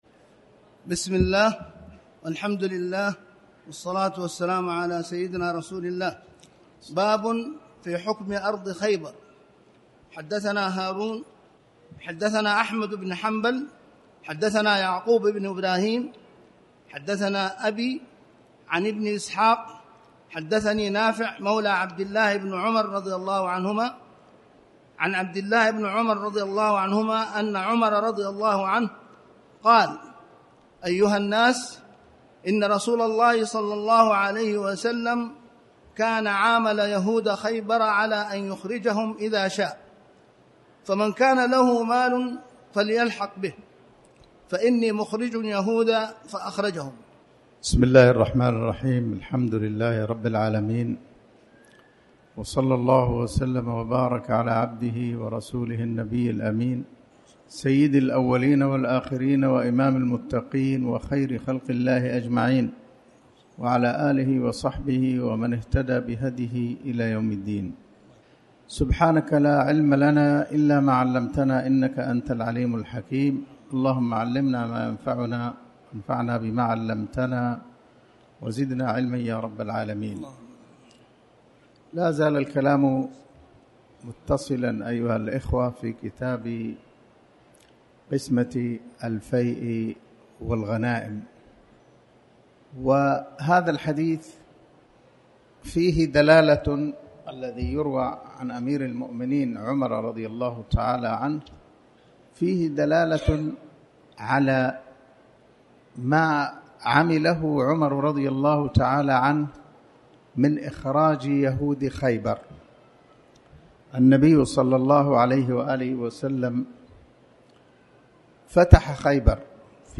تاريخ النشر ١٦ شوال ١٤٣٩ هـ المكان: المسجد الحرام الشيخ